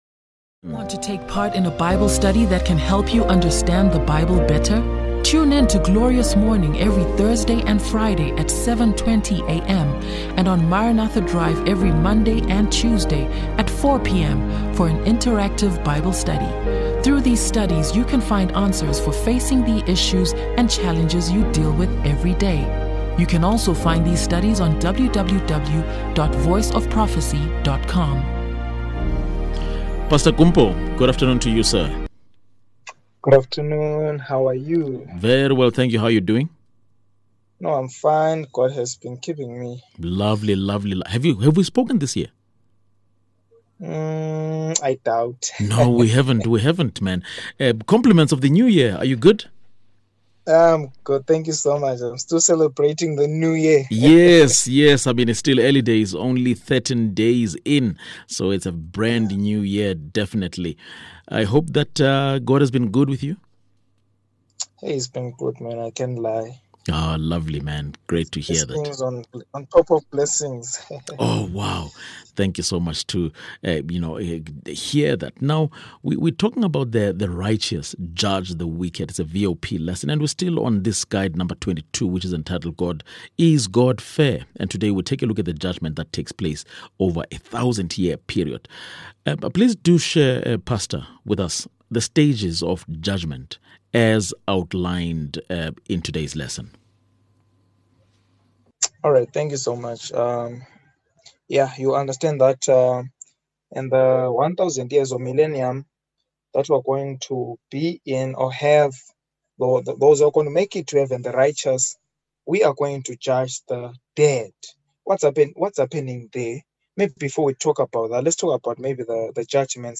ENGLISH SOUTH AFRICA